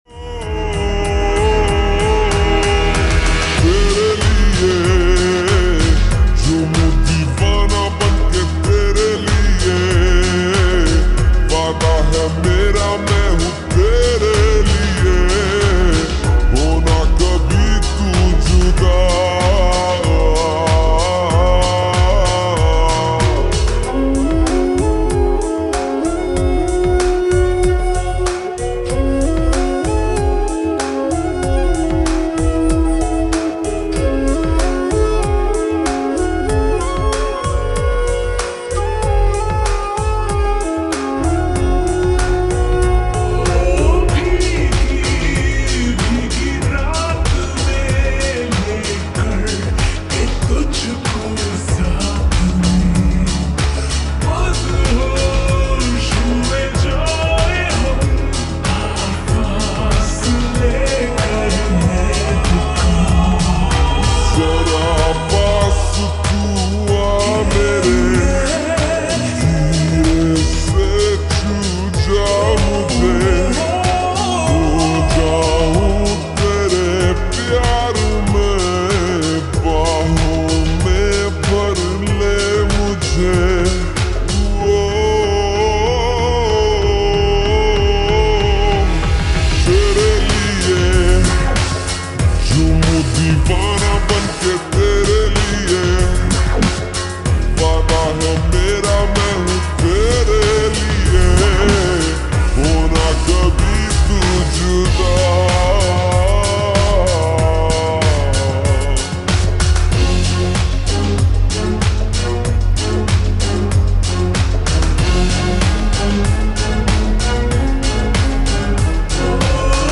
(slow+reverb),lofi,Lovely mood song